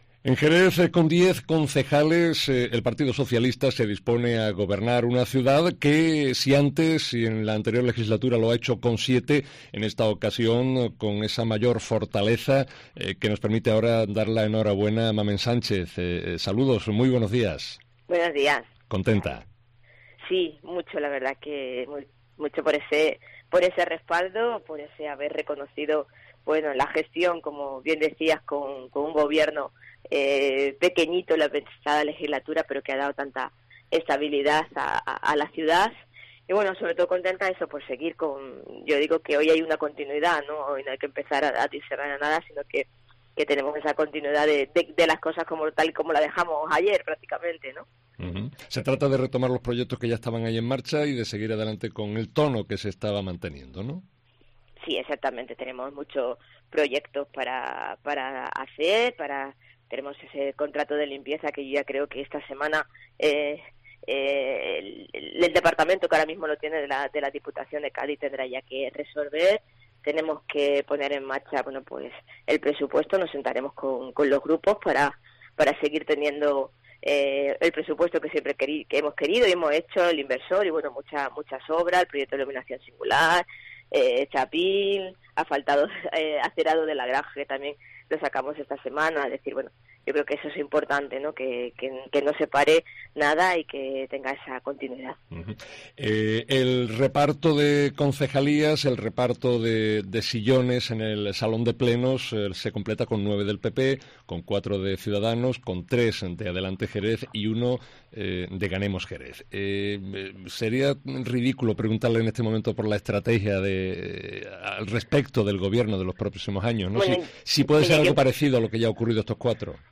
Mamen Sánchez, de nuevo alcaldesa de Jerez, habla en COPE